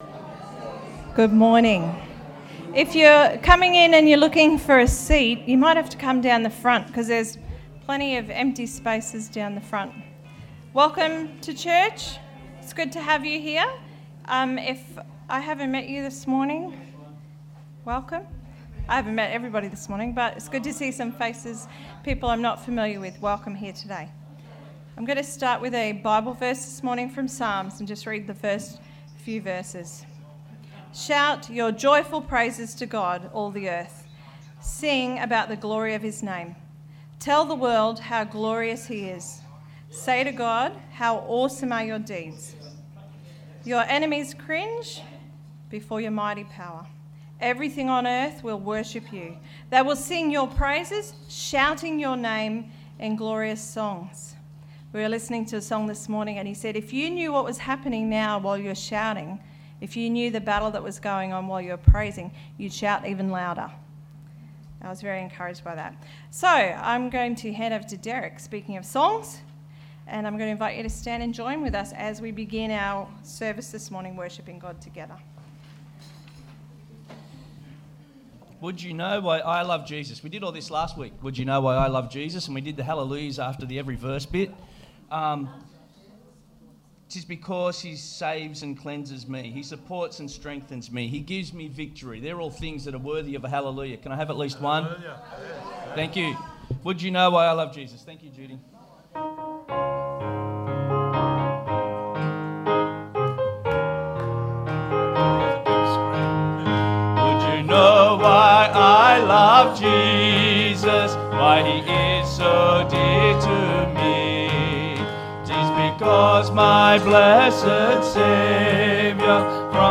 Sunday_Meeting_7th_March_2021_Audio.mp3